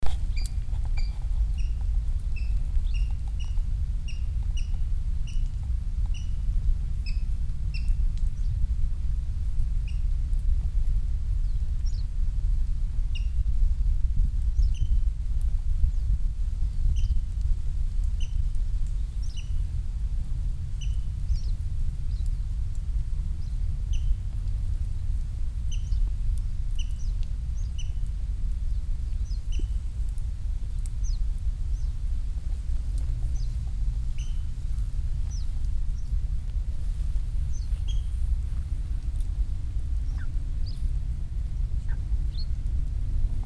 Had we been driving, we might have missed the brief, piercing call of the rail.
The sound was coming from the reeds at the landward base of the sea wall.
rail-brown-cheeked002-Rallus-indicus.mp3